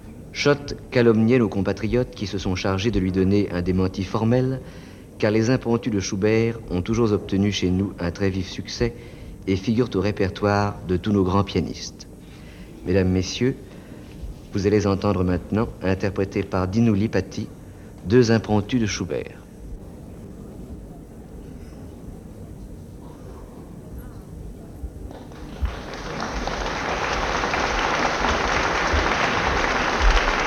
Lipatti, Dinu (1917-1950). P. | Chopin, Frédéric (1810-1849).
Applaudissements et arpèges
Musique classique -- Musique instrumentale soliste